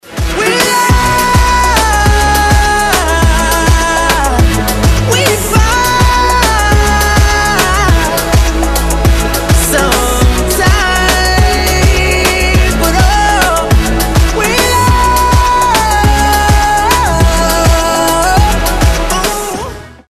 • Качество: 256, Stereo
поп
dance
Electronic
vocal